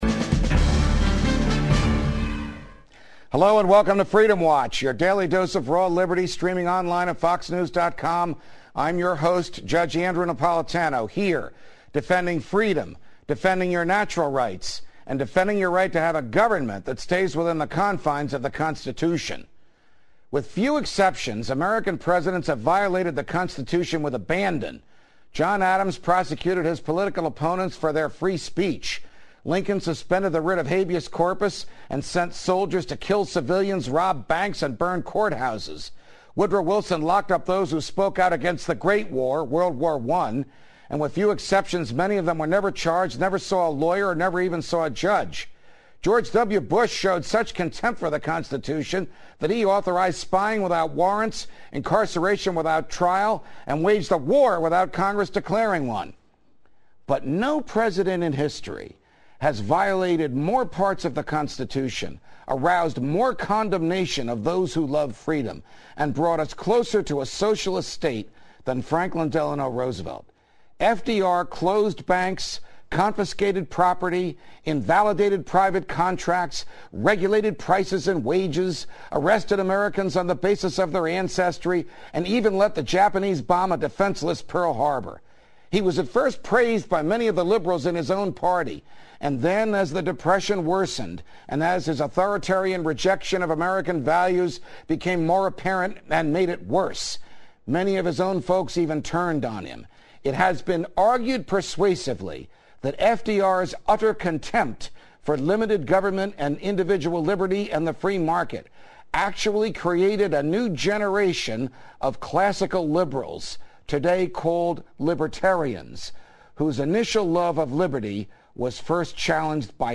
appeared on Fox News' Freedom Watch with Judge Andrew Napolitano to discuss how the New Deal eroded liberty and how FDR's policies caused some leading liberals to turn right.